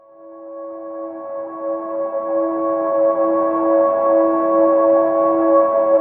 04PAD 01  -L.wav